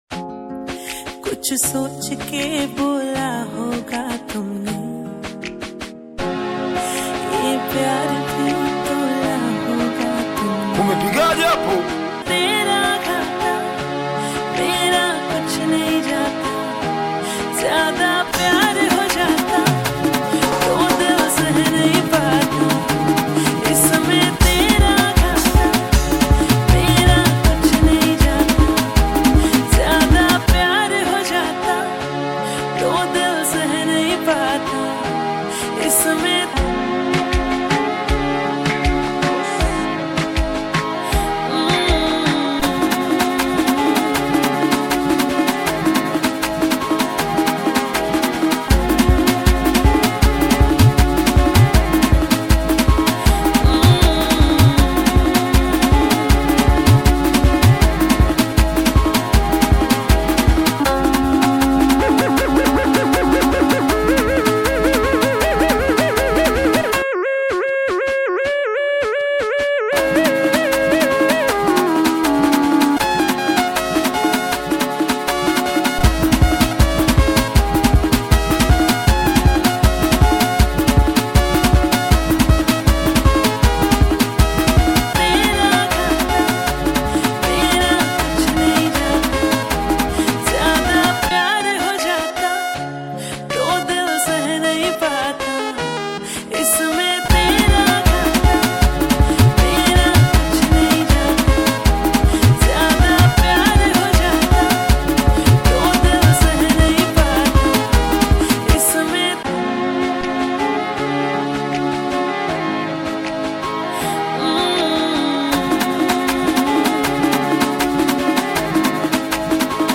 Bongo Flava
Singeli